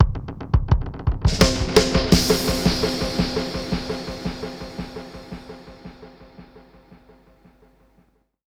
Index of /musicradar/dub-drums-samples/85bpm
Db_DrumsA_KitEcho_85_04.wav